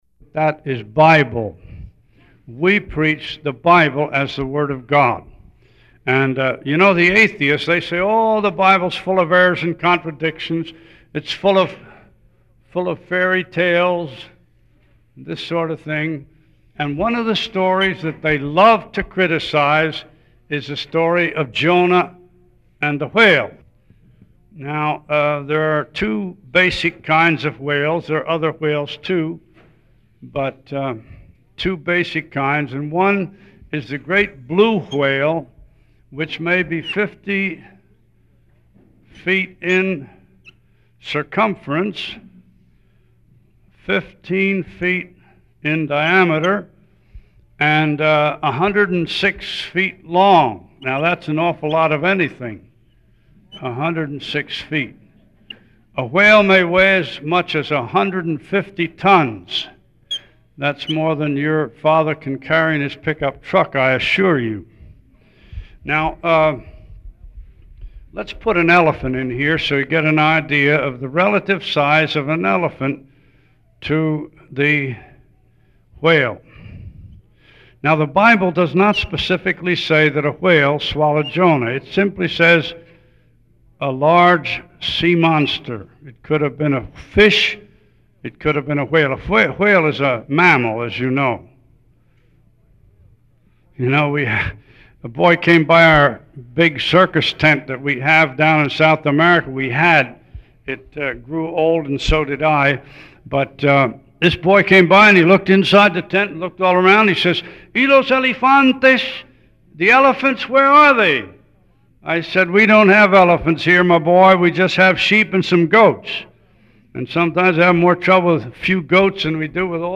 Testimony: Losing Your Life for Christ — Brick Lane Community Church